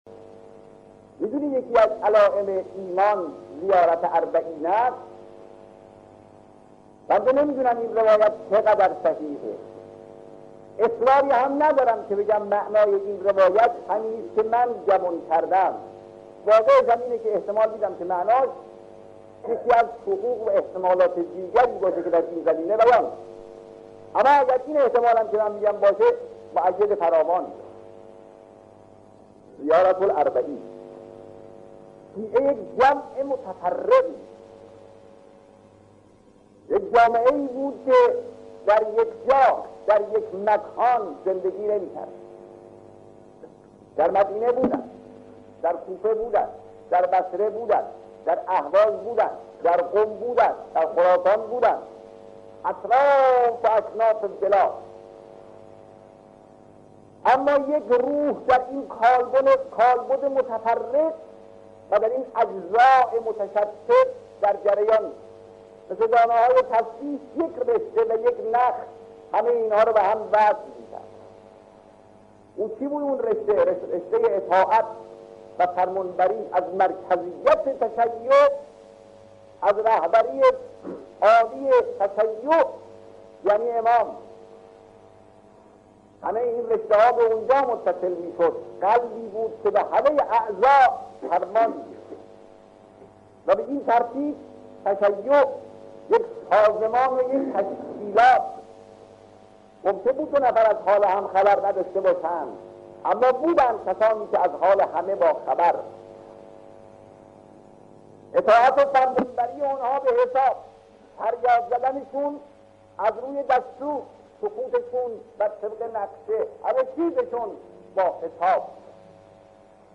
گزیده‌ای سخنرانی حضرت آیت‌الله خامنه‌ای در روز اربعین سال ۱۳۵۲ شمسی در مسجد امام حسن مجتبی (علیه‌السلام) شهر مشهد مقدس.